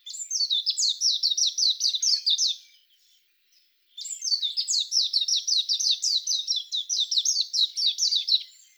ヘルプ 詳細情報 メジロ 大きさ スズメくらい 季節 春夏秋冬 色 緑 特徴 全長11cmでスズメより小さく、目の回りが白く愛らしい鳥。